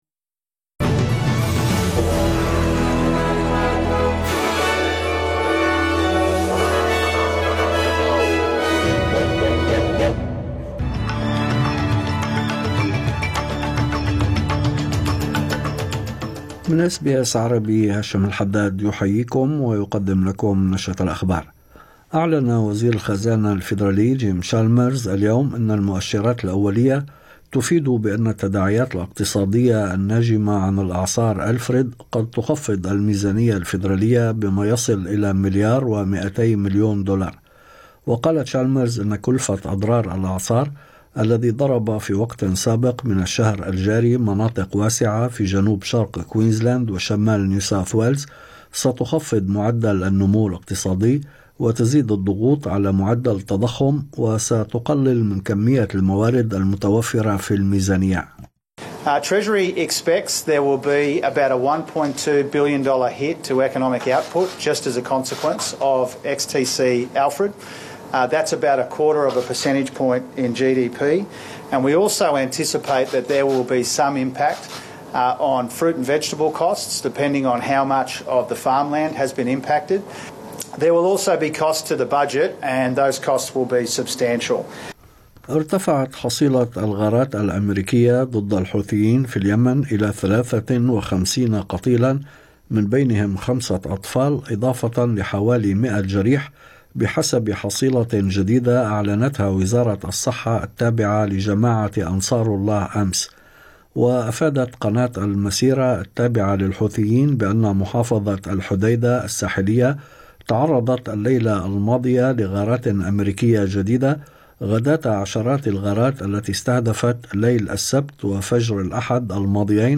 نشرة أخبار الظهيرة 17/3/2025